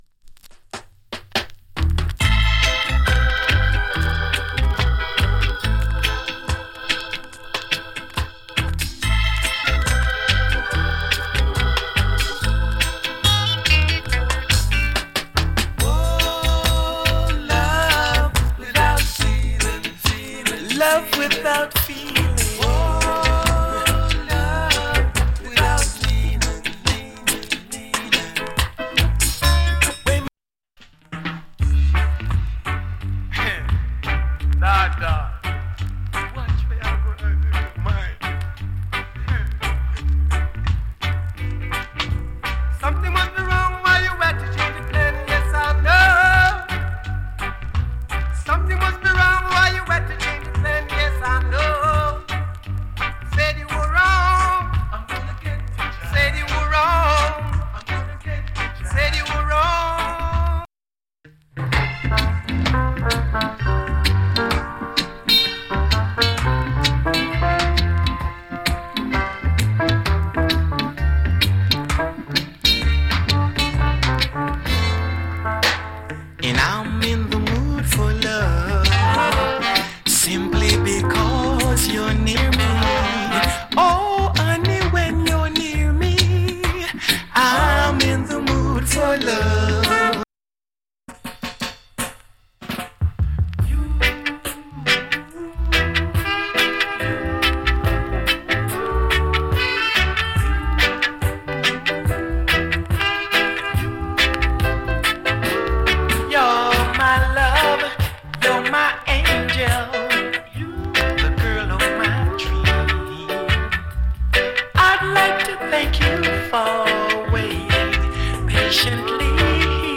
プレスによるノイズが入る部分有り。